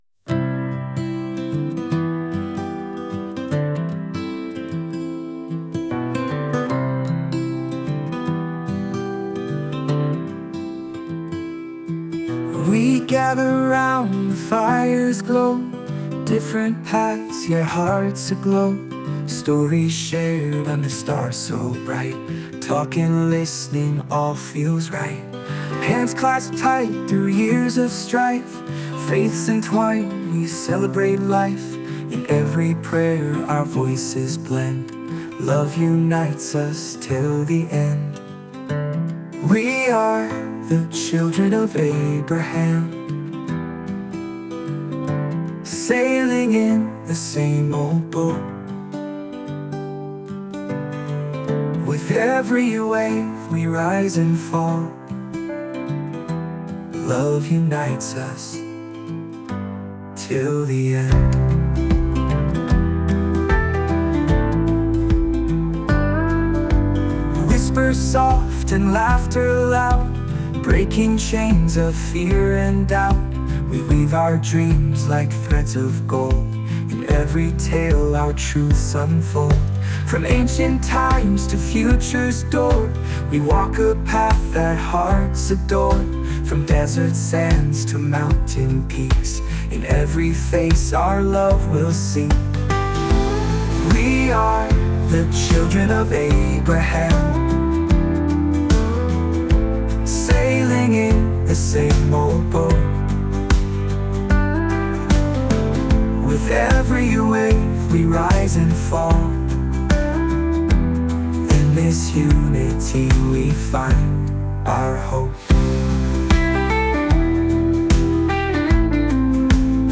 3 minute AI (Artificial Intelligence) Song, 2-20-25